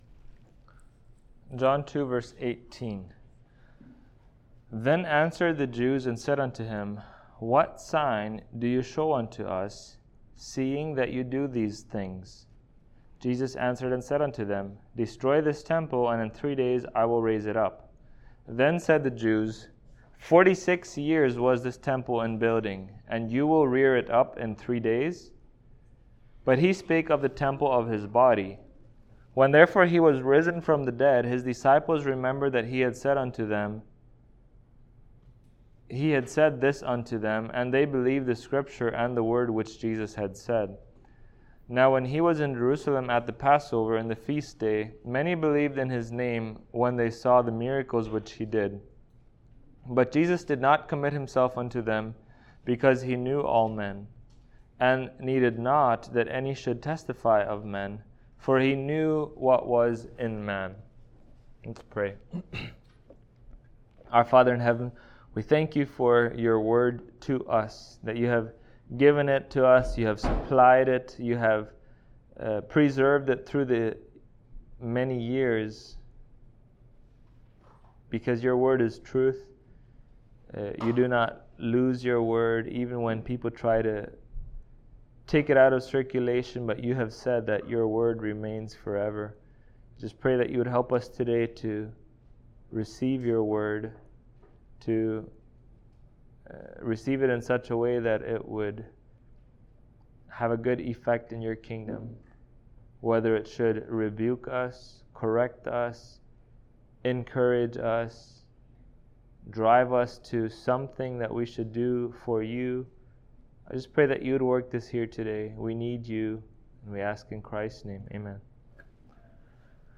John Passage: John 2:18-25 Service Type: Sunday Morning Topics